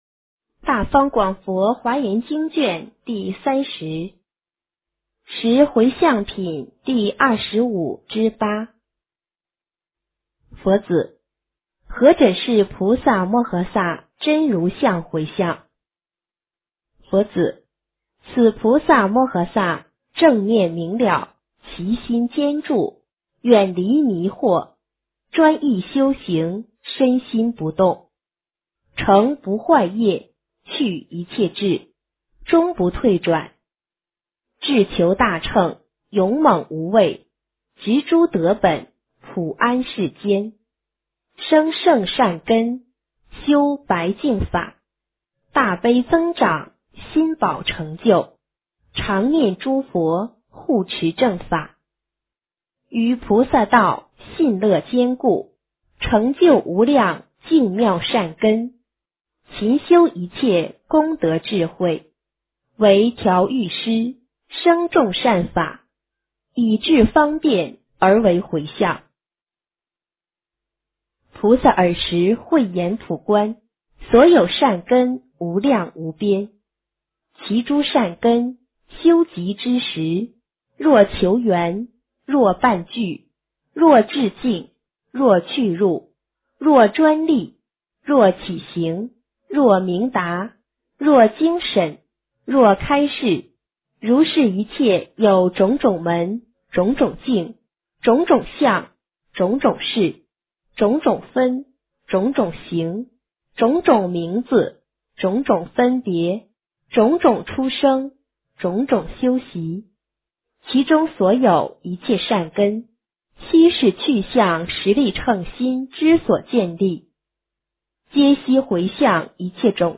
华严经30 - 诵经 - 云佛论坛